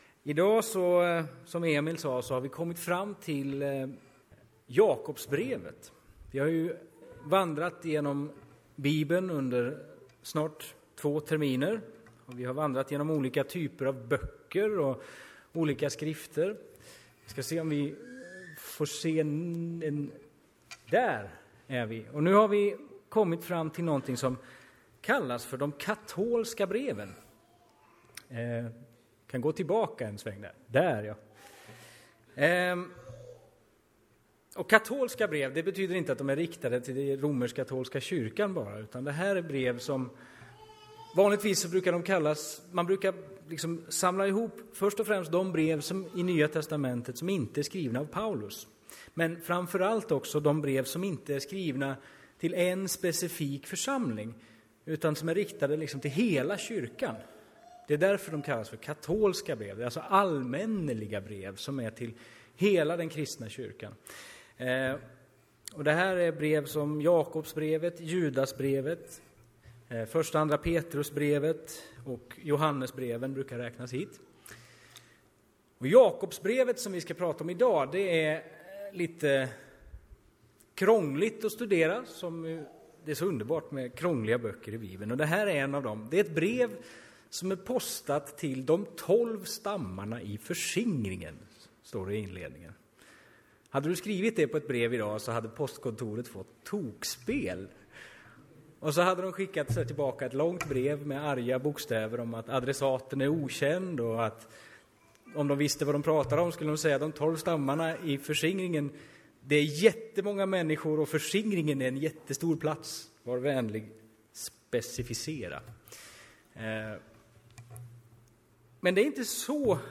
Predikoserie: Genom NT (våren 2018)